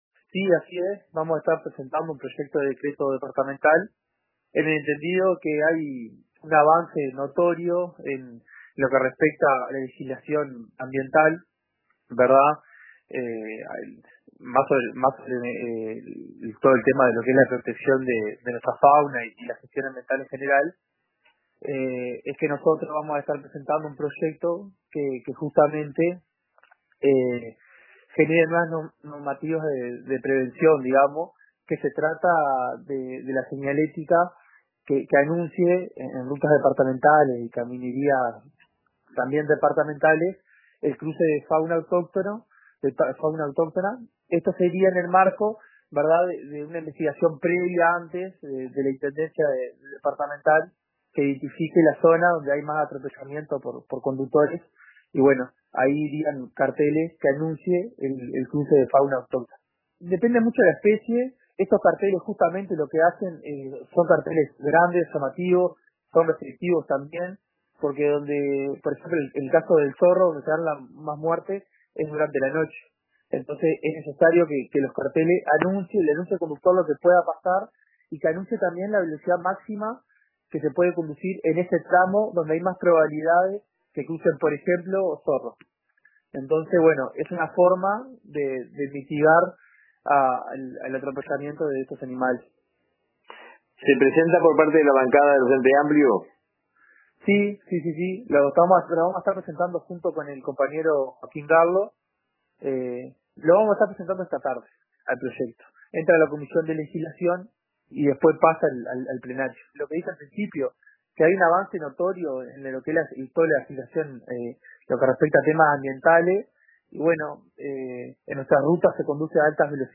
En una entrevista con RADIO RBC, el Edil frenteamplista explicó que estos carteles serían grandes, llamativos y reflectivos.